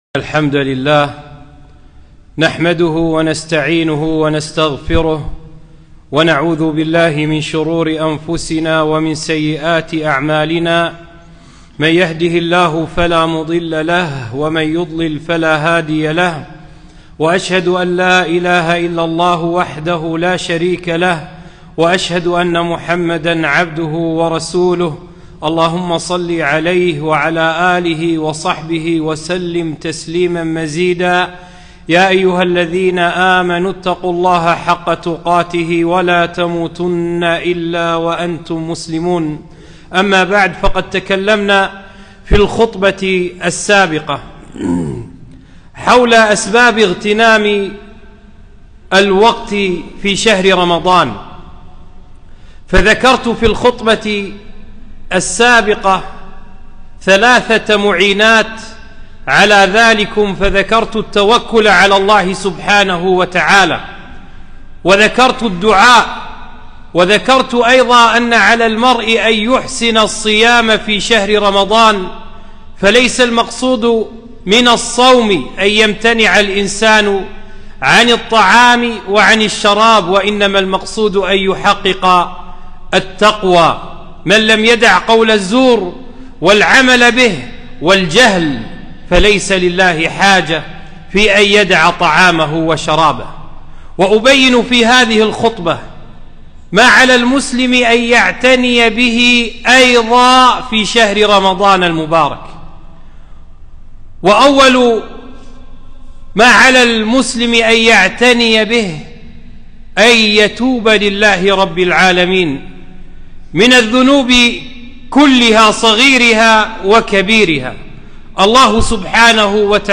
خطبة - أتاكم رمضان (2)